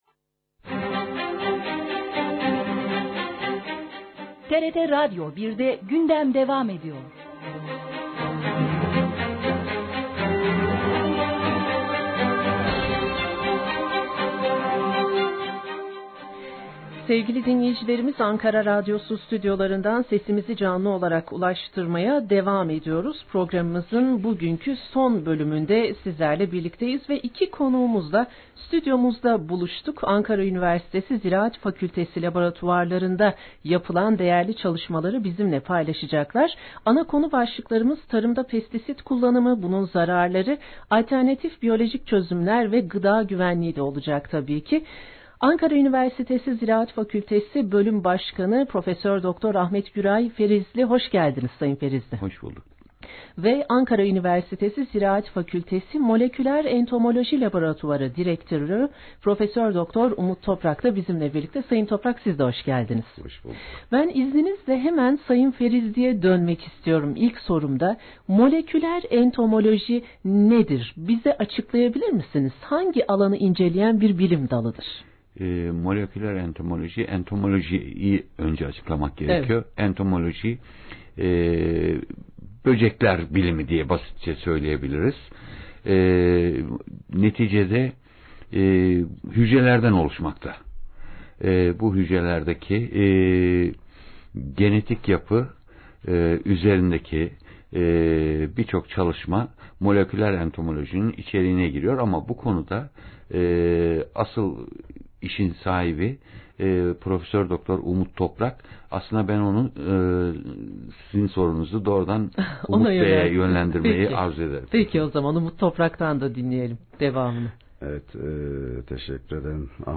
TRT Radyo 1’de Gündem Programının konuğu oldu.